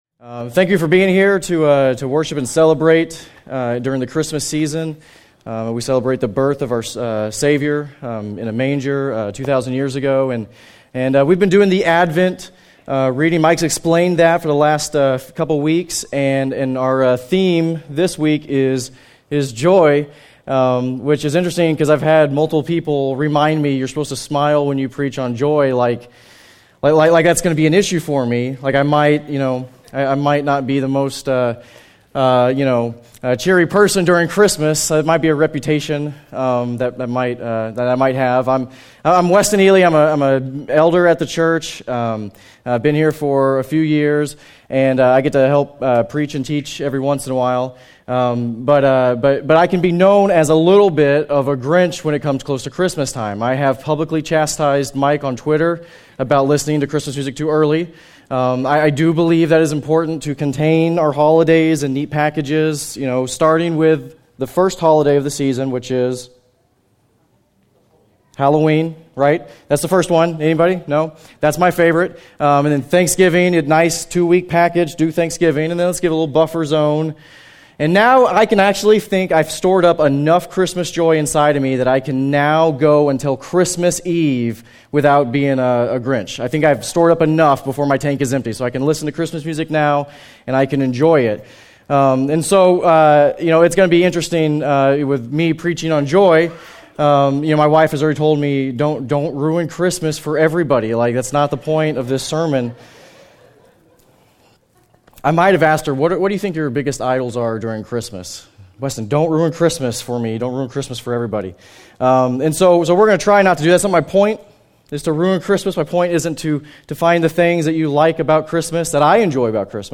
Special Sermon